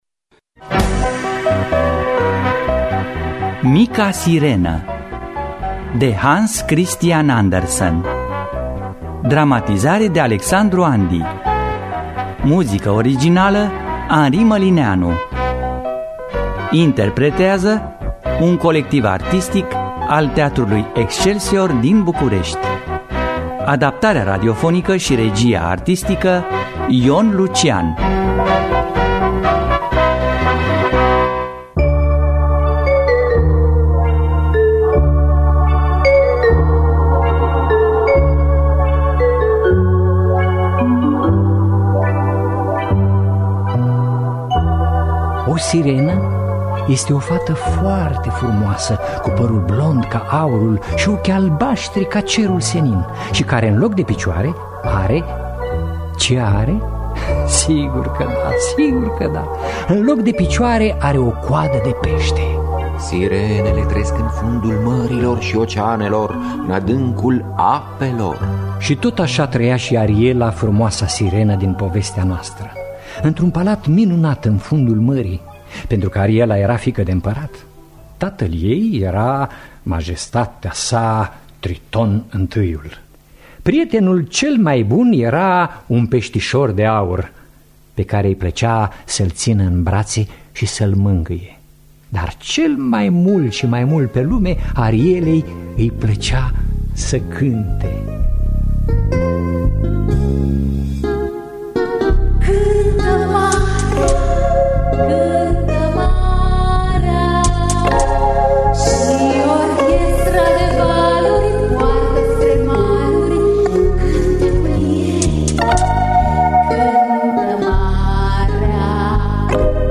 Mica sirenă de Hans Christian Andersen – Teatru Radiofonic Online